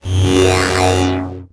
VEC3 Reverse FX
VEC3 FX Reverse 52.wav